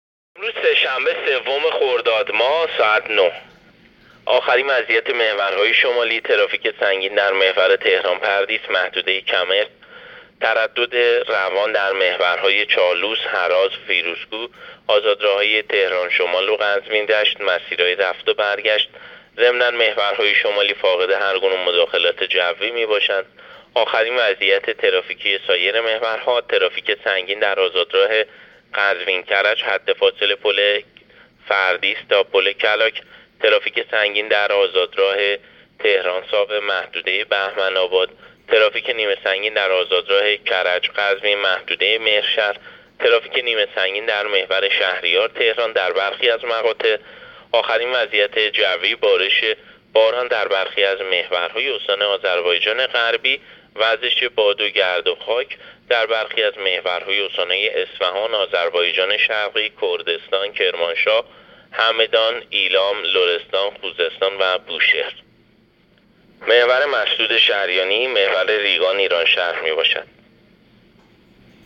گزارش رادیو اینترنتی از آخرین وضعیت ترافیکی جاده‌ها تا ساعت ۹ سوم خرداد؛